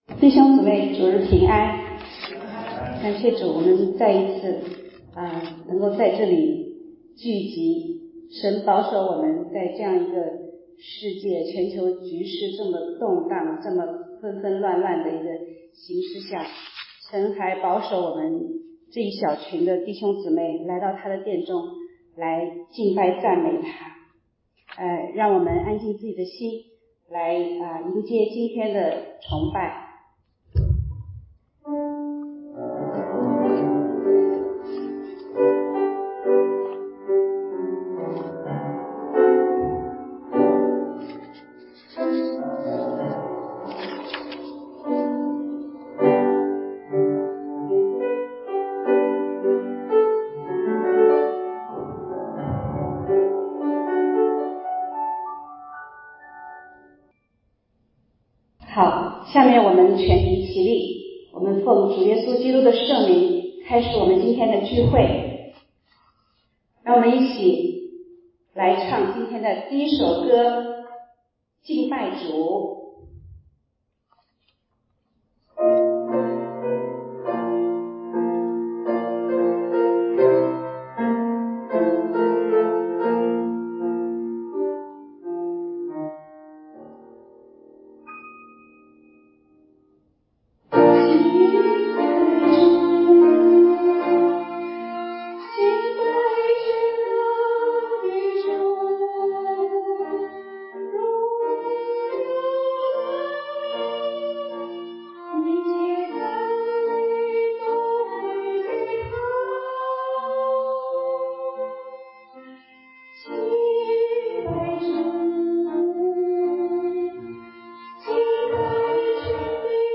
8-29-21敬拜-CD.mp3